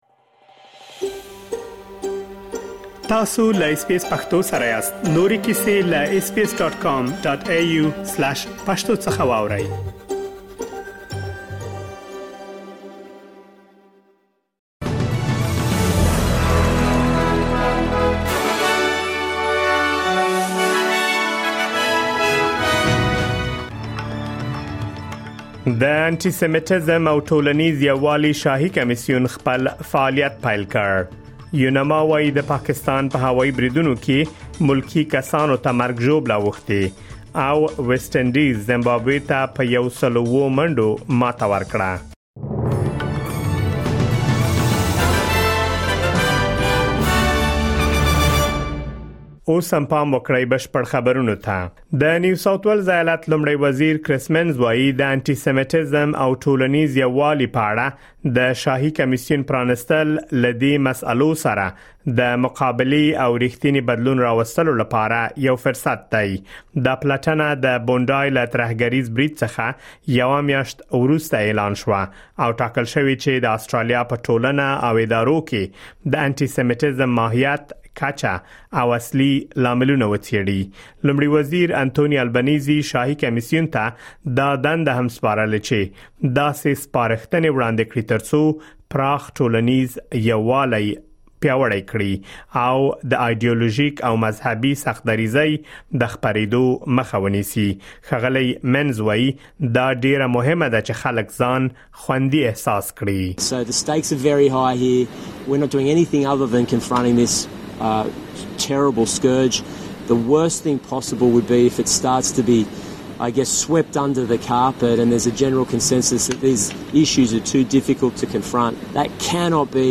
د اسټراليا او نړۍ مهم خبرونه
د اس بي اس پښتو د نن ورځې لنډ خبرونه دلته واورئ.